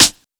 Snares
Csp_Snr2.wav